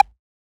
edm-perc-26.wav